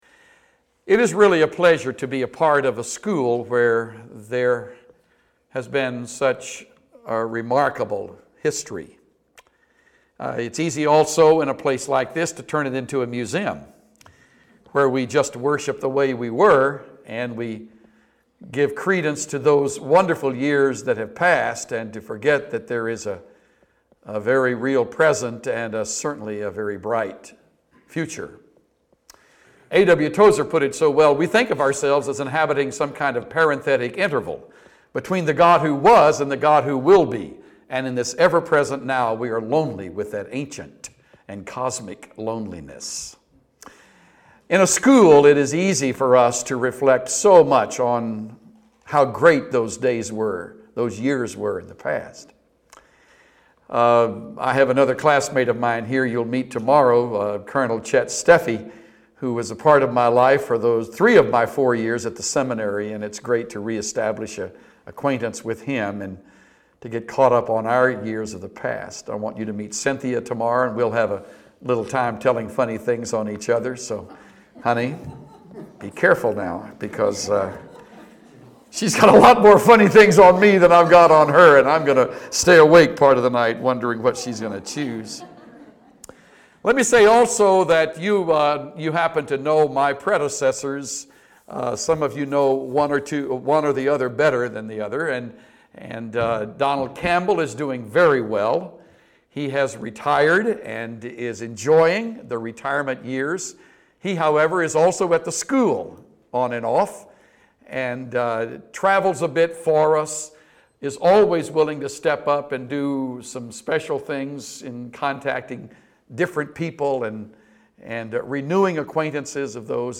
Introduction to the Sermon Theme